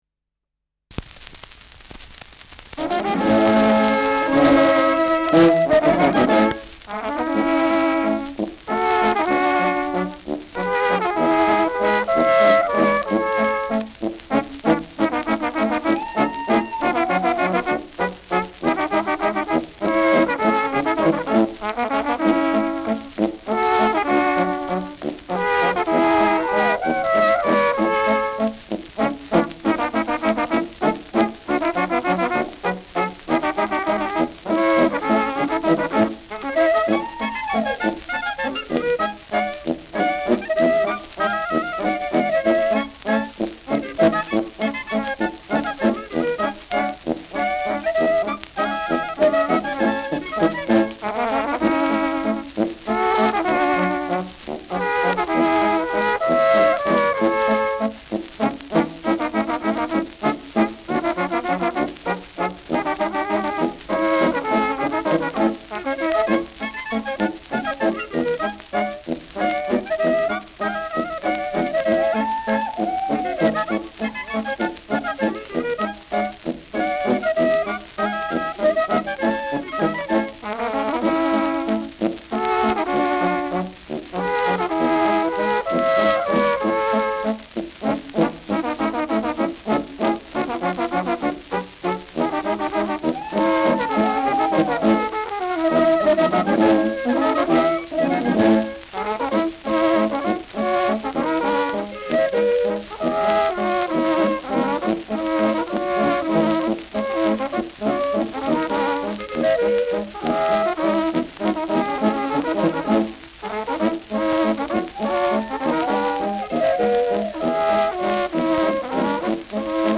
Schellackplatte
präsentes Rauschen
Mit Juchzern.